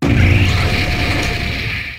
slitherwing_ambient.ogg